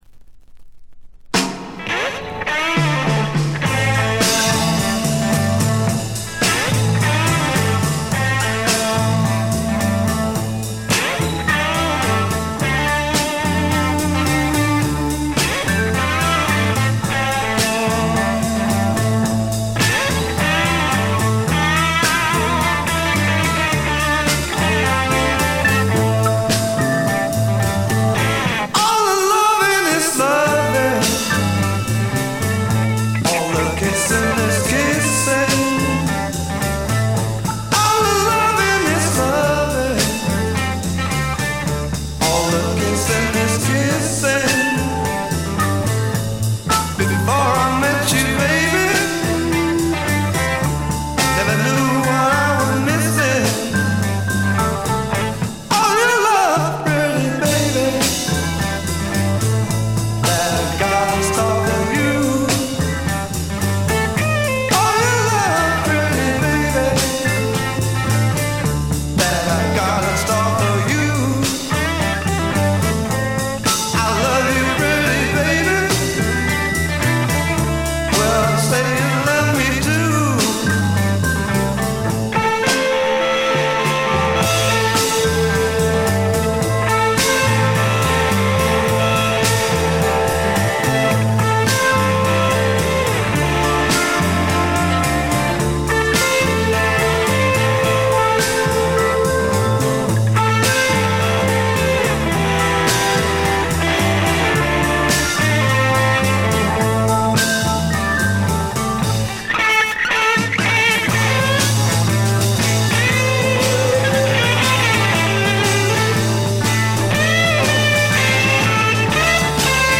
静音部（曲間しかないけど）で微細なチリプチが聴かれる程度でほとんどノイズ感無し。
モノラル・プレス。
ちょいと音量を上げれば暴風が吹き荒れるような感じ。
試聴曲は現品からの取り込み音源です。
lead vocals, piano, Hammond B3 organ, harmonica
bass guitar
drums